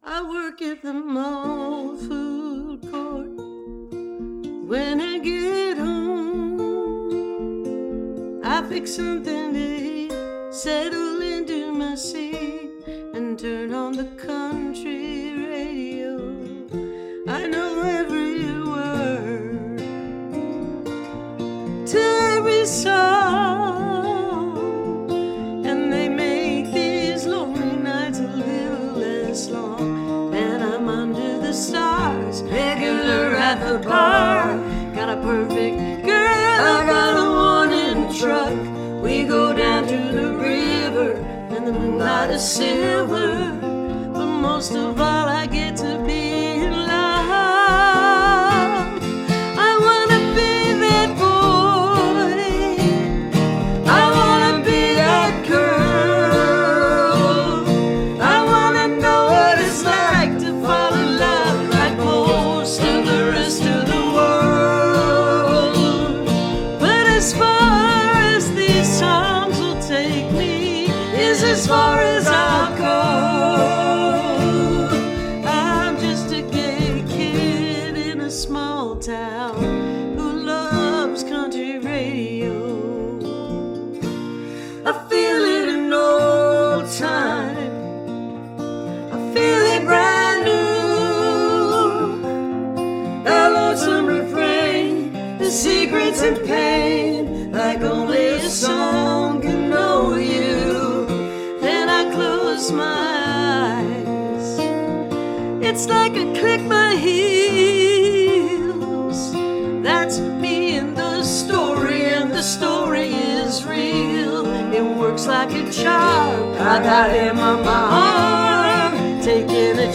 (captured from youtube videos)